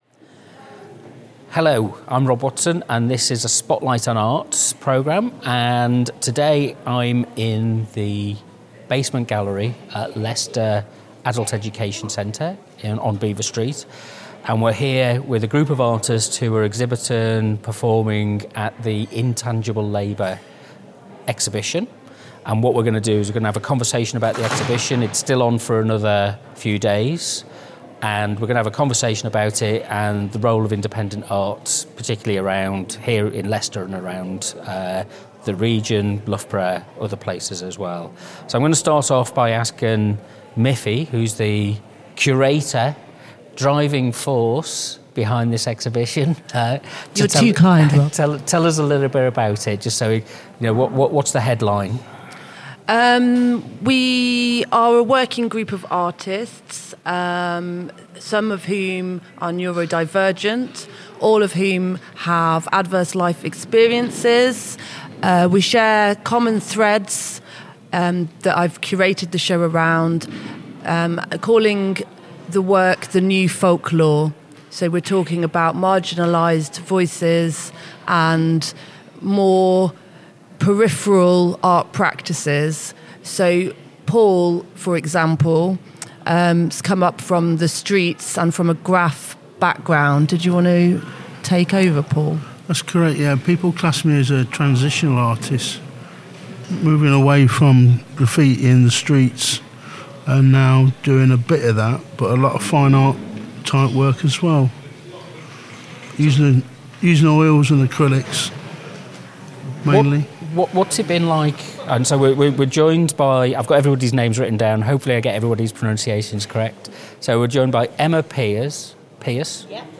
This discussion for Spotlight on Arts was recorded in the basement gallery at the Adult Education Centre during the Intangible Labour exhibition, bringing together a group of artists whose practices sit deliberately outside dominant institutional pathways. What emerges most clearly is not a single aesthetic position, but a shared experience of working independently in a system that routinely demands artists chase funding, approval, and legitimacy at the expense of time, wellbeing, and creative focus.